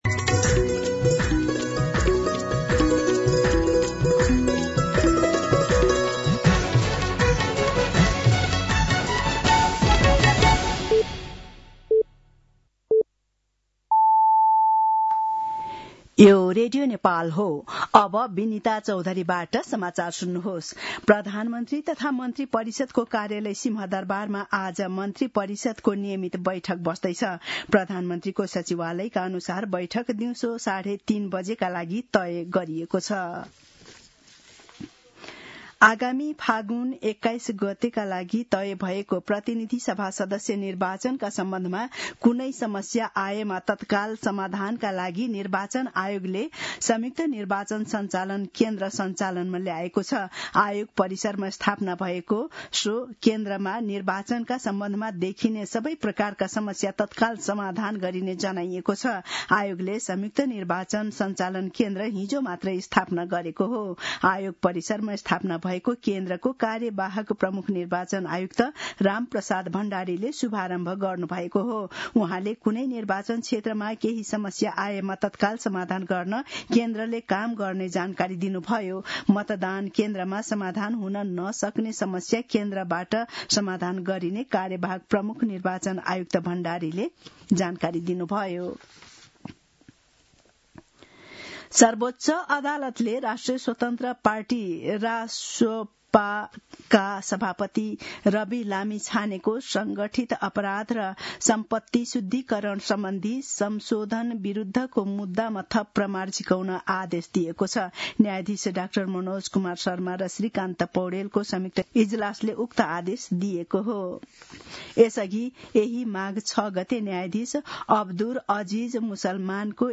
मध्यान्ह १२ बजेको नेपाली समाचार : १२ माघ , २०८२